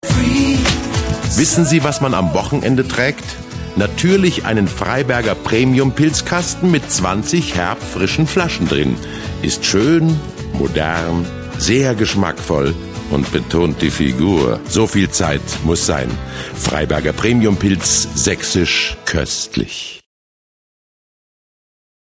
Werbespot